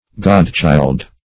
Godchild \God"child`\, n.